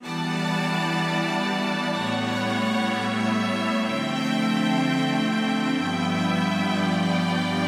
中国古筝主奏
与前一个循环中的中国风格乐器相同。
标签： 125 bpm Ethnic Loops Strings Loops 1.29 MB wav Key : Unknown
声道立体声